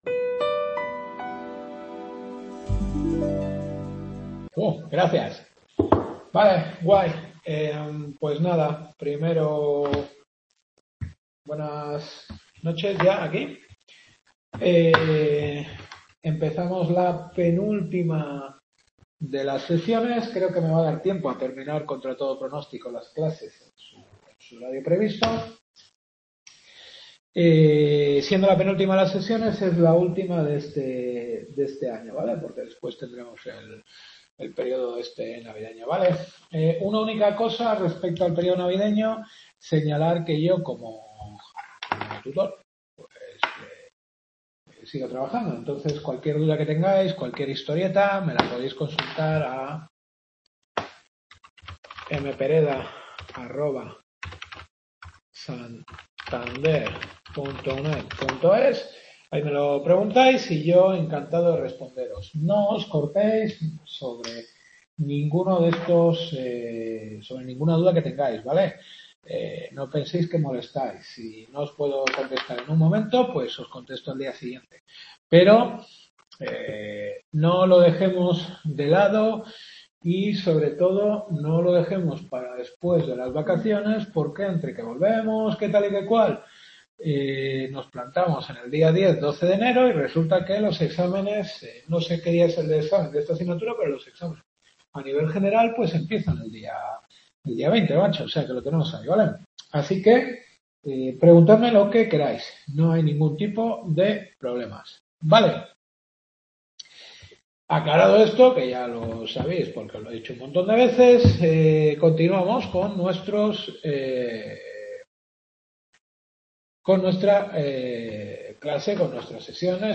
Historia del delito y de las penas. Undécima clase.